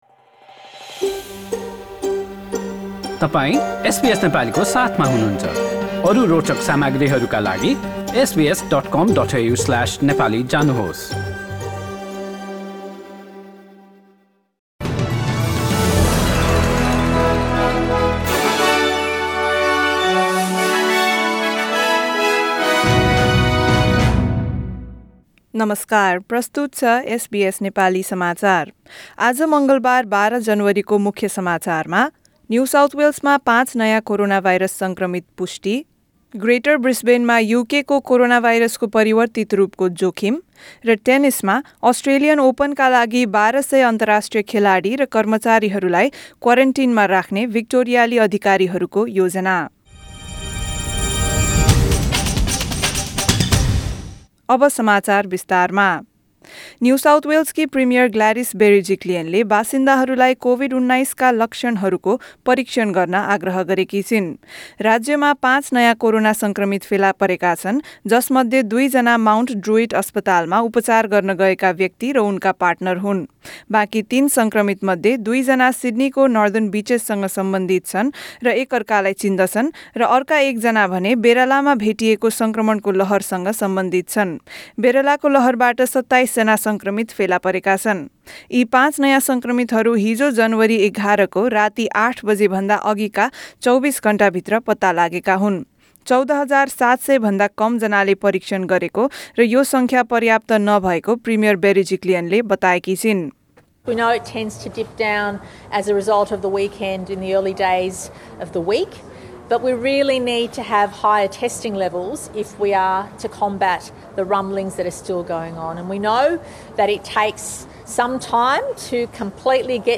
एसबीएस नेपाली अस्ट्रेलिया समाचार: मङ्गलबार १२ जनवरी २०२१